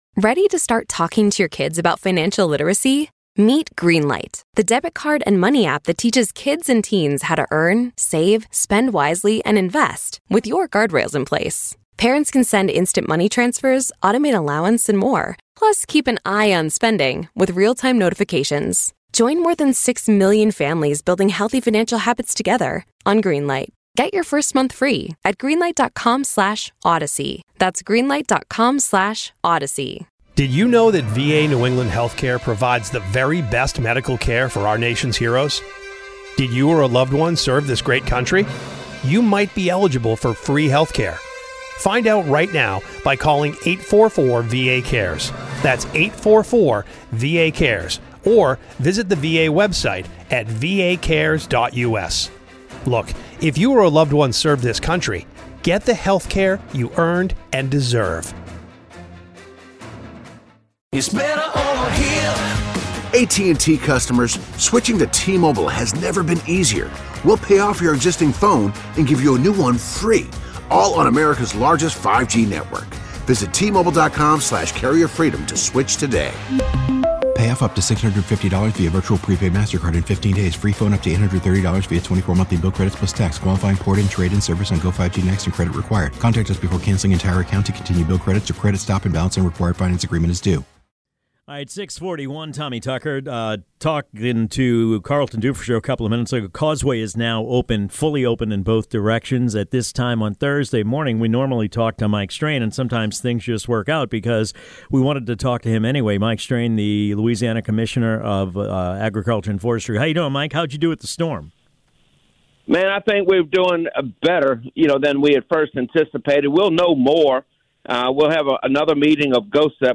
talks with Mike Strain, Commissioner of the Louisiana Department of Agriculture & Forestry